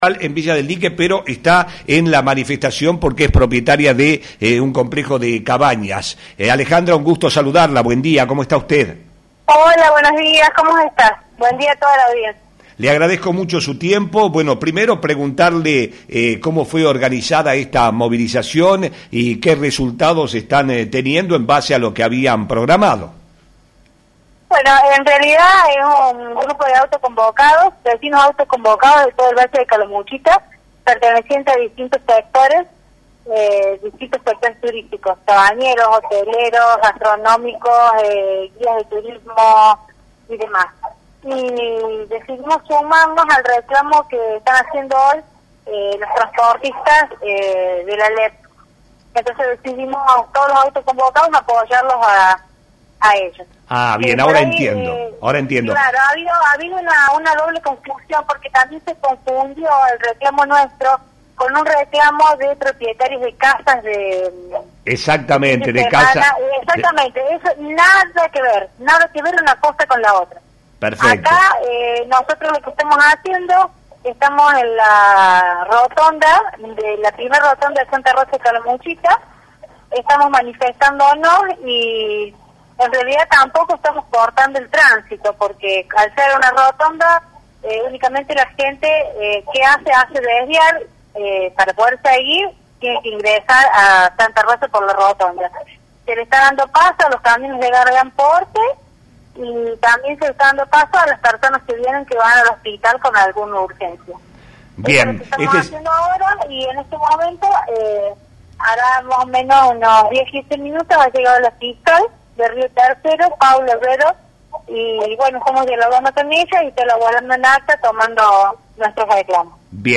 En diálogo con Flash FM